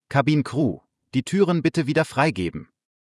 DisarmDoors.ogg